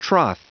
Prononciation du mot troth en anglais (fichier audio)